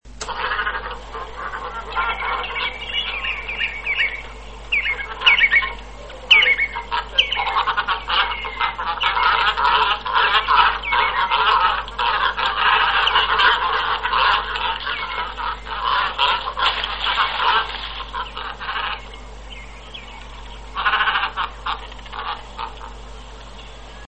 Kormoran czarny - Phalacrocorax carbo
głosy